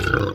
Hellephant Hurt.wav